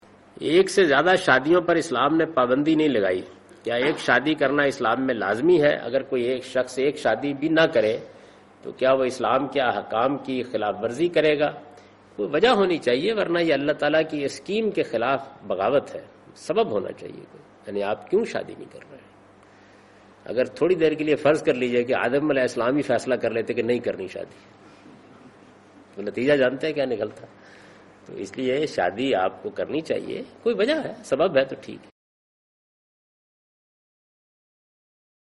Javed Ahmad Ghamidi responds to the question 'Is marrying atleast once required by Islamic law'?